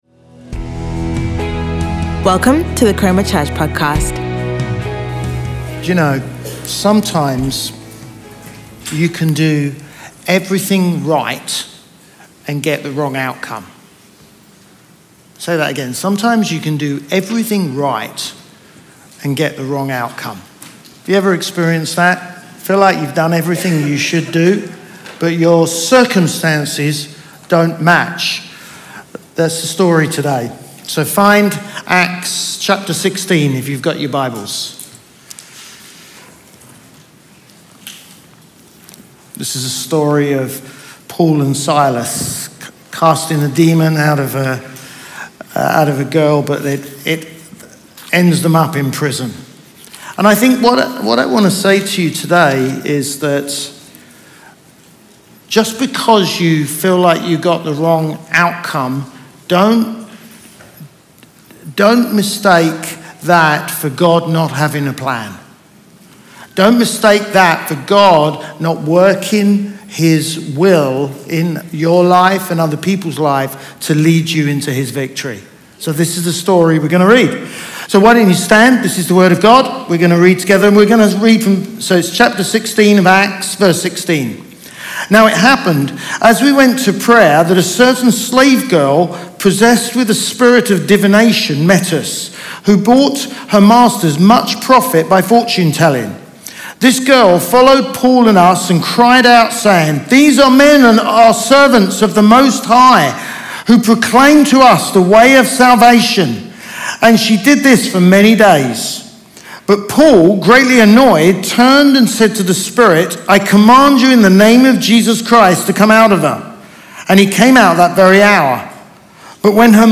Chroma Church - Sunday Sermon